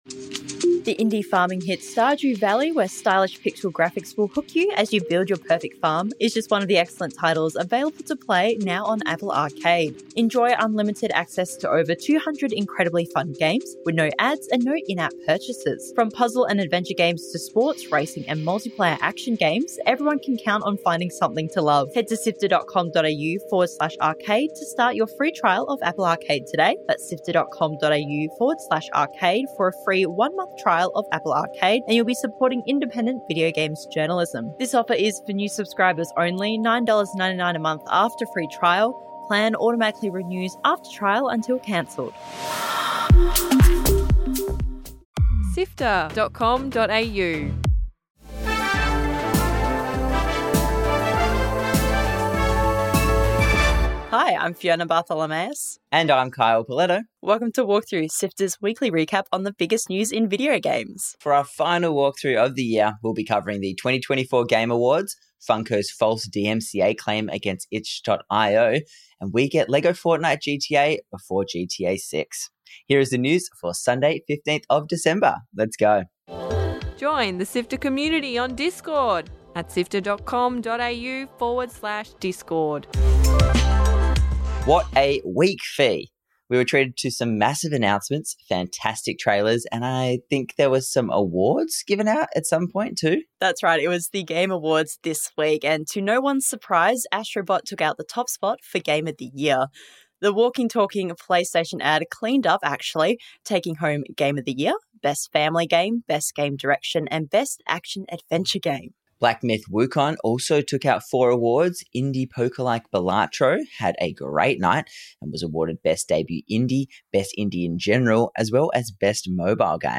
Latest was J4GM+RR Jatiluwih, Tabanan Regency, Bali, Indonesia - Splashing water.
1 J4GM+RR Jatiluwih, Tabanan Regency, Bali, Indonesia - Splashing water Play Pause 1d ago Play Pause Play later Play later Lists Like Liked — Water splashing in an overfall of the subak irrigation system which supplies the rice fields with water..